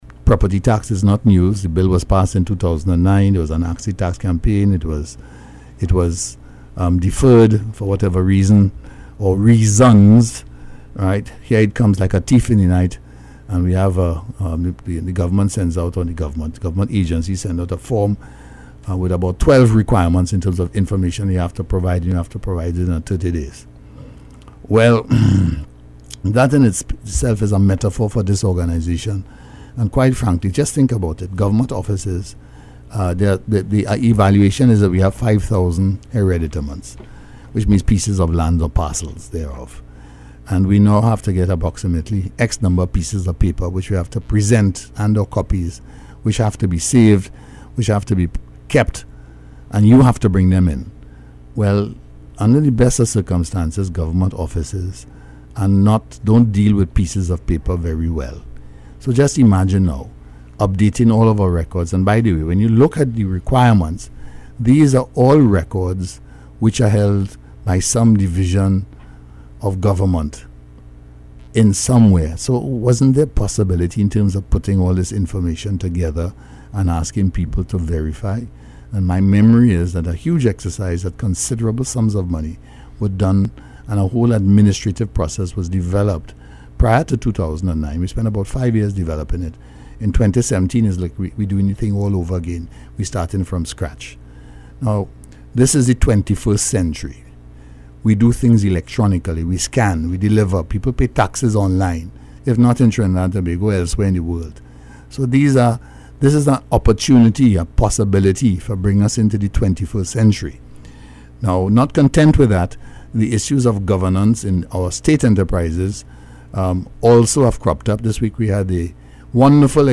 This is my interview